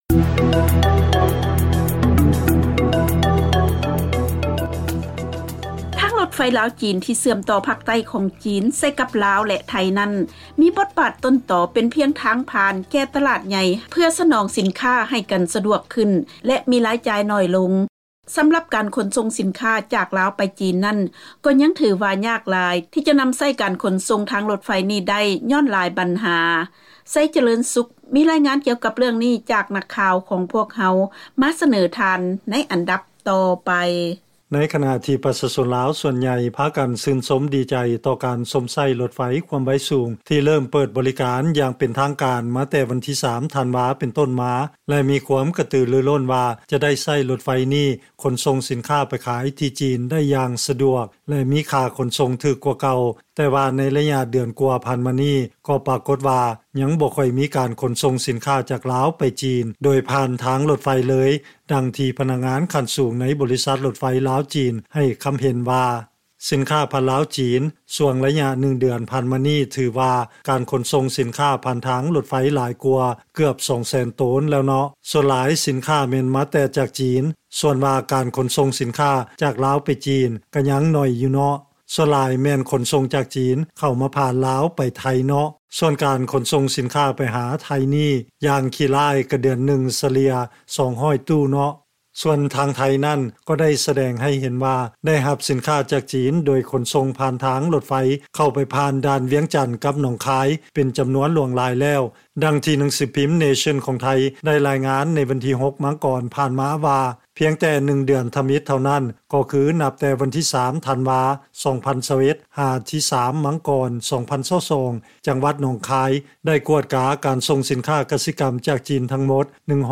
ເຊີນຟັງລາຍງານ ທາງລົດໄຟລາວ-ຈີນ ທີ່ເຊື່ອມຕໍ່ພາກໃຕ້ຂອງຈີນ ໃສ່ກັບ ລາວແລະໄທ ໃຊ້ເປັນທາງຜ່ານສຳລັບສິນຄ້າຈາກຕະຫຼາດໃຫຍ່